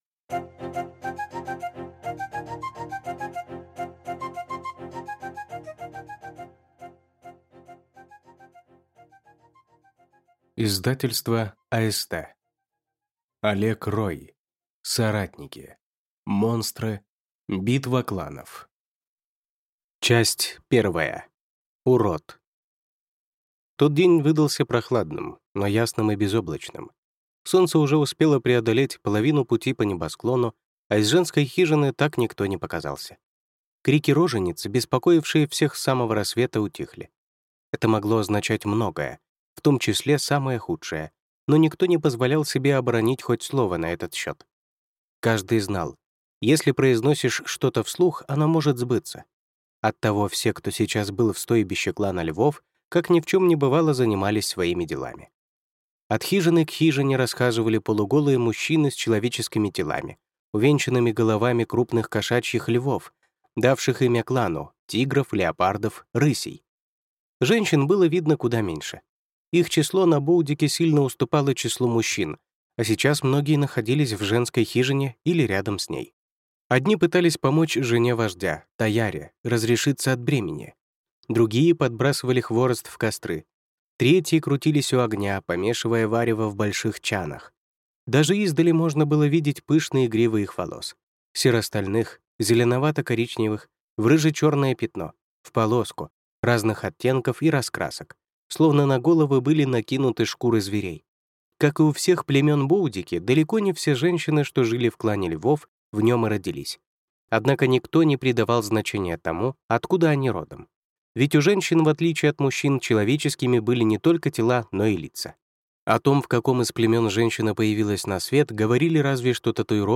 Кровь Василиска. Том 3 (слушать аудиокнигу бесплатно) - автор Тайниковский